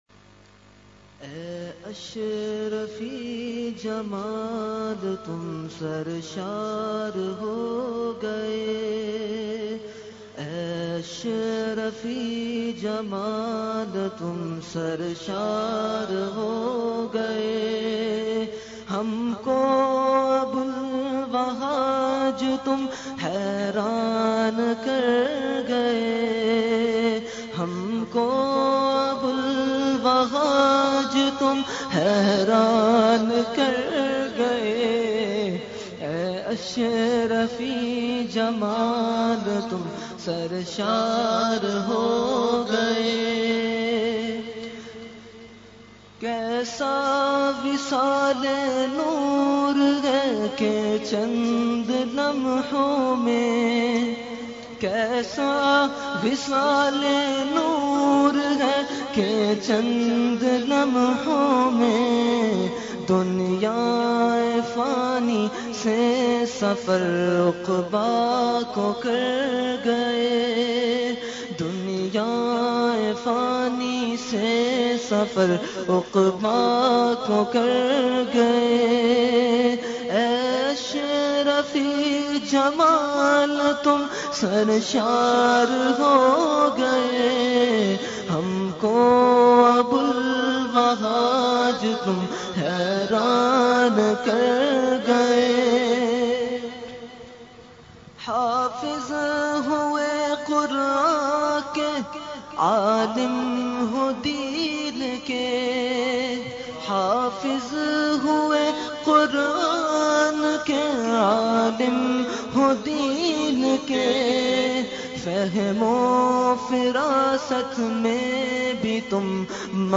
Category : Manqabat